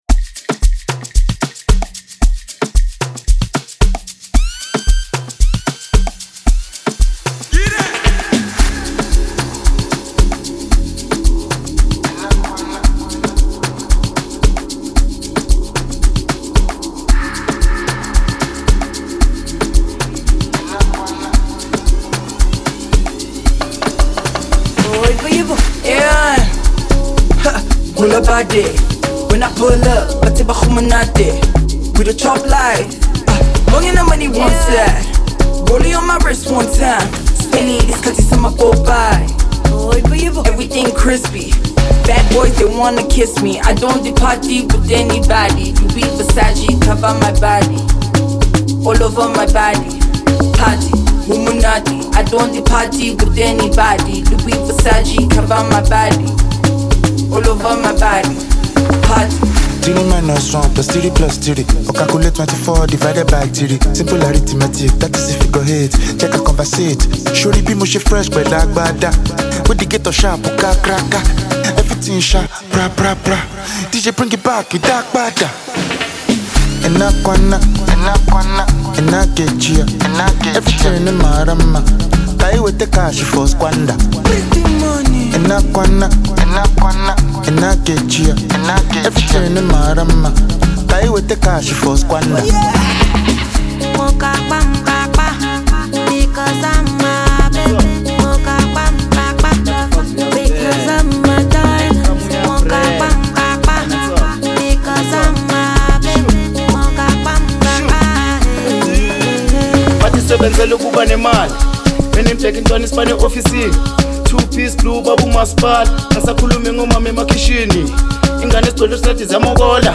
is an infectious song that would keep you dancing.